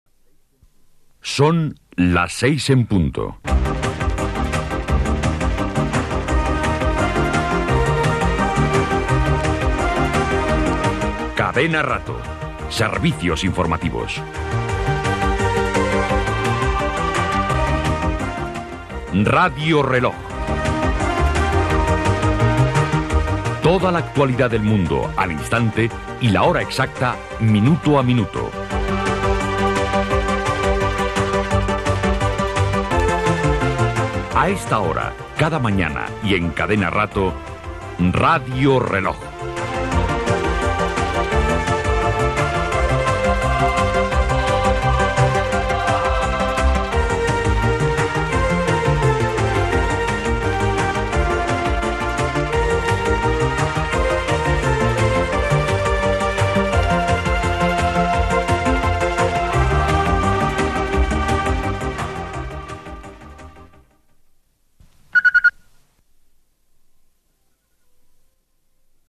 Hora i identificació del programa informatiu de primera hora del matí.
Informatiu